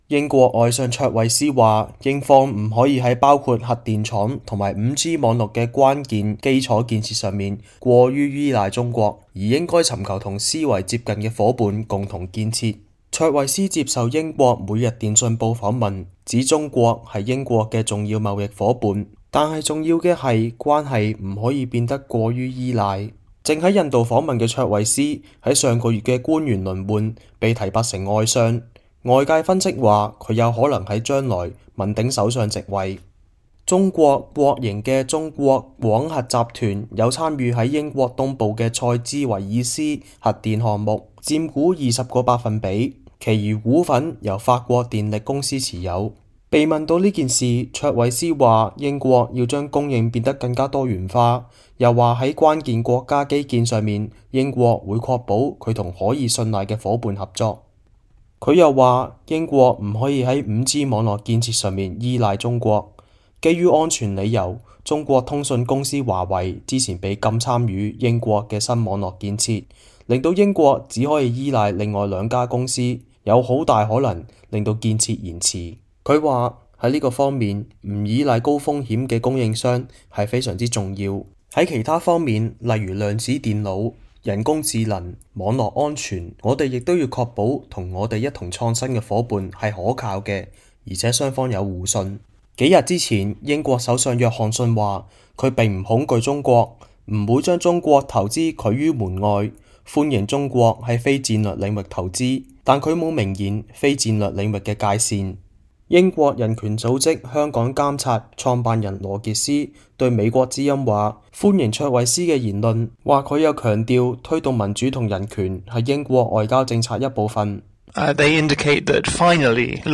倫敦 —